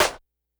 snr_52.wav